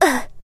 Mig17_normalhit.mp3